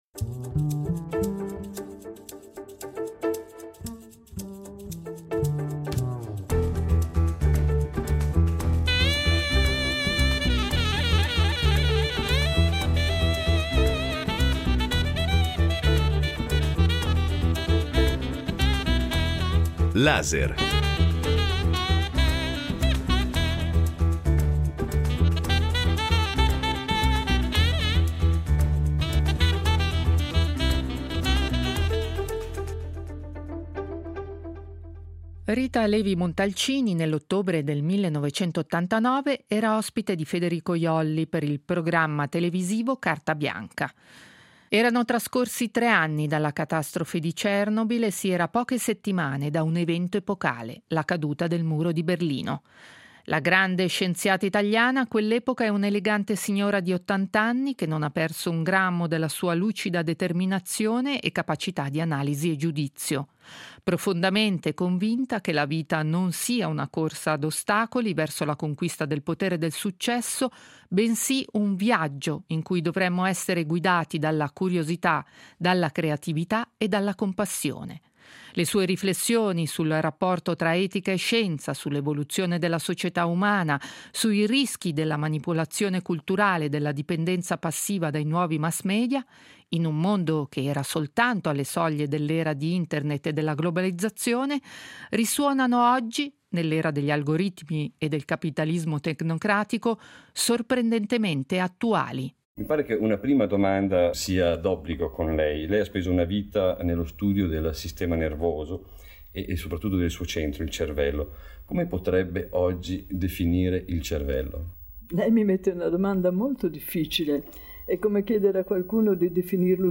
Intervista a Rita Levi Montalcini in collaborazione con gli Archivi RSI